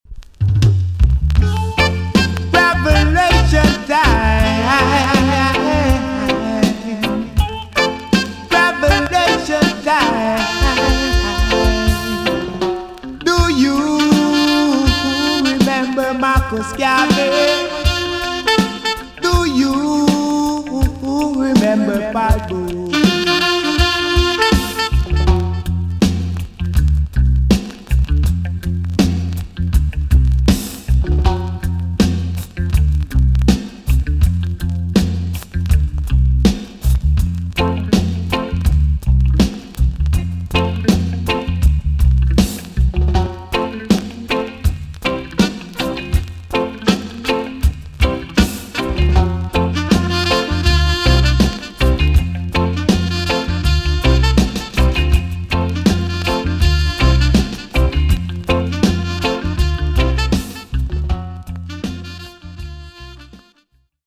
TOP >80'S 90'S DANCEHALL
B.SIDE Version
EX- 音はキレイです。
1984 , NICE EARLY DANCEHALL TUNE!!